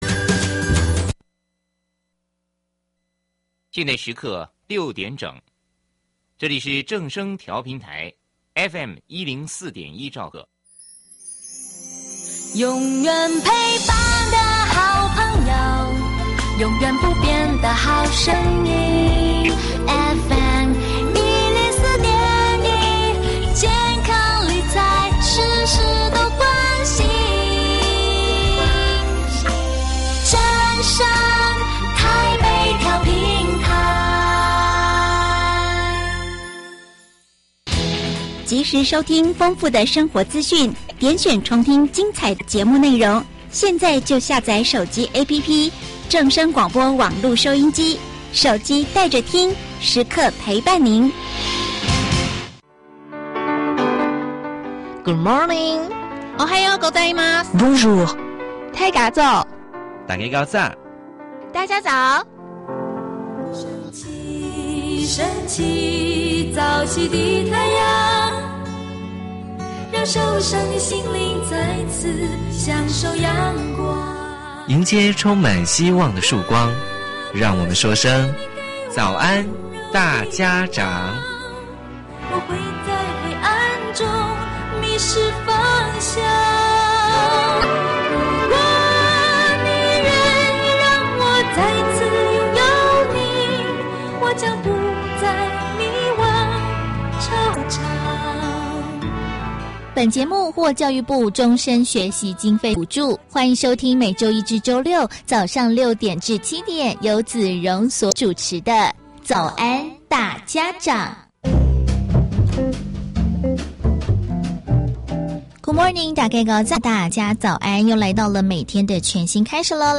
0600時節目主持人